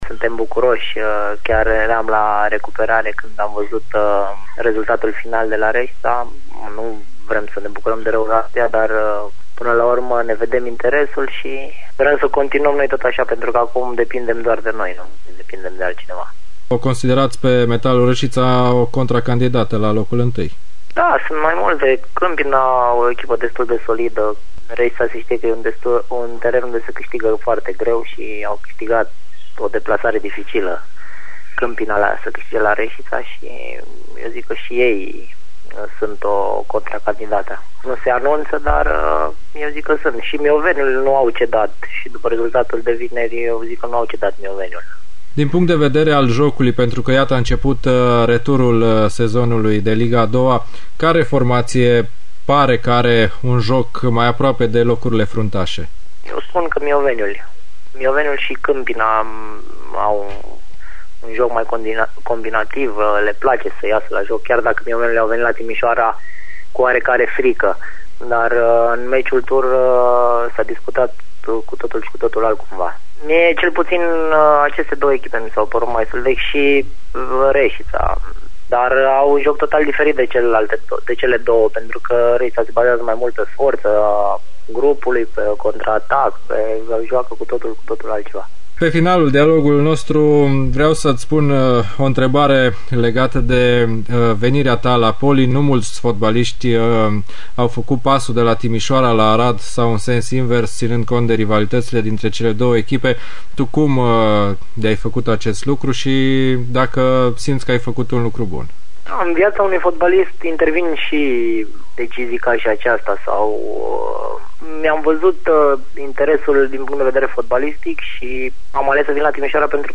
inteviul